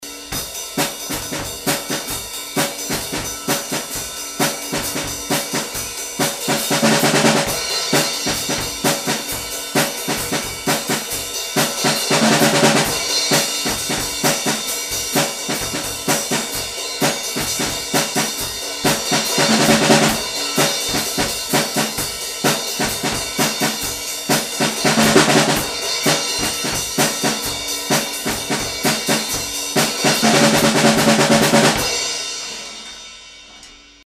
snarefill_speciaal.mp3